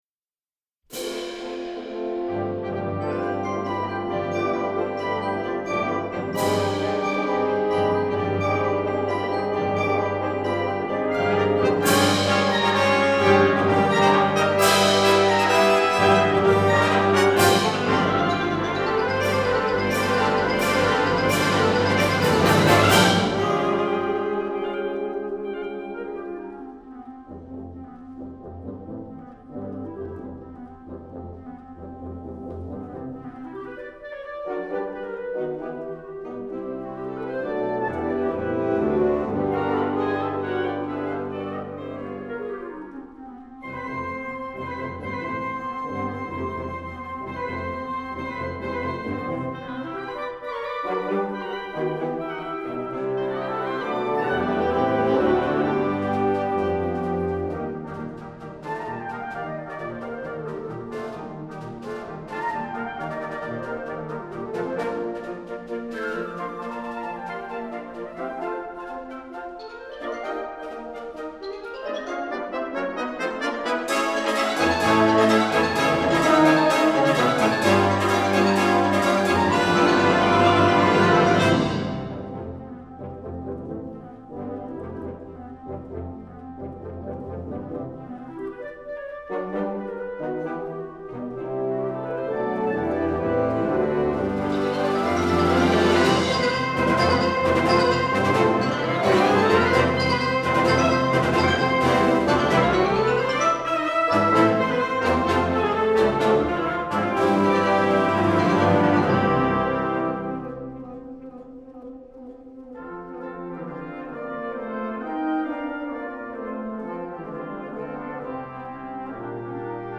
Gattung: Konzertmarsch
Besetzung: Blasorchester
Dieser moderne und energiegeladene Konzertmarsch